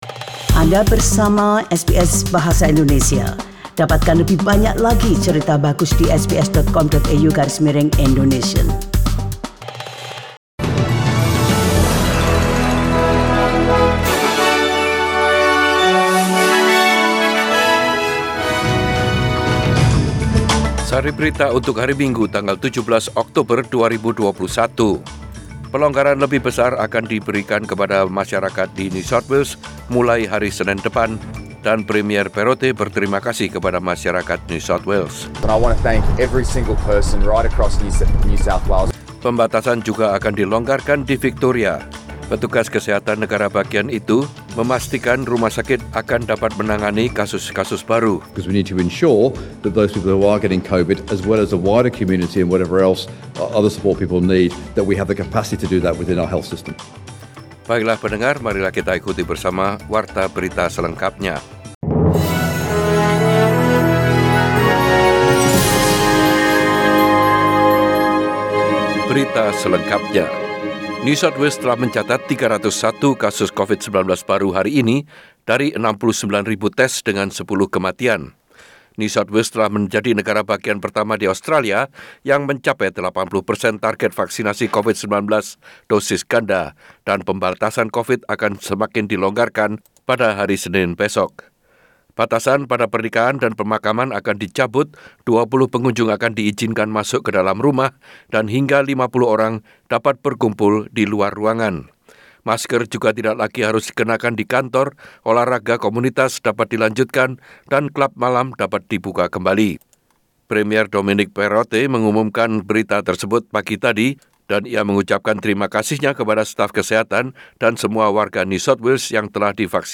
SBS RAdio News in Bahasa Indonesia - 17 October 2021
Warta Berita Radio SBS Program Bahasa Indonesia Source: SBS